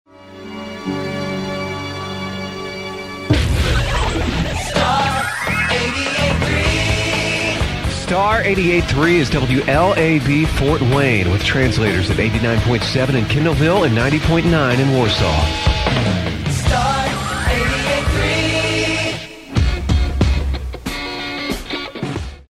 WLAB Top of the Hour Audio: